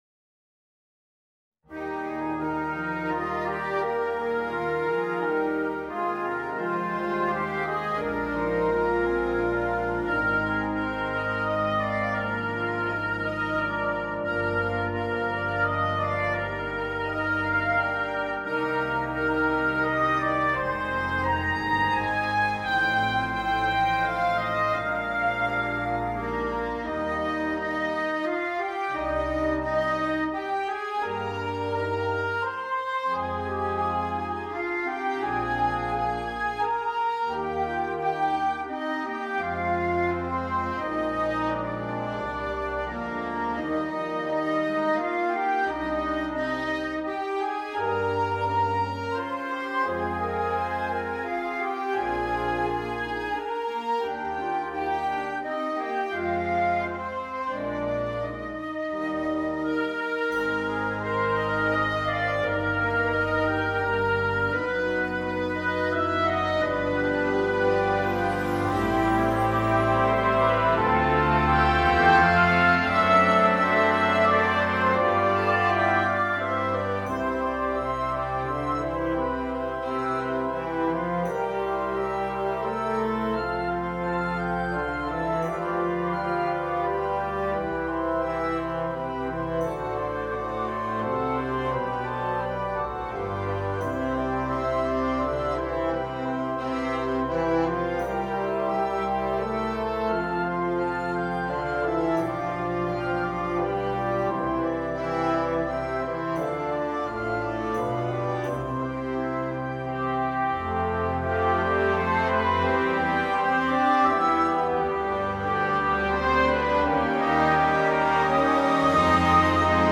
Gattung: Concert Band
Besetzung: Blasorchester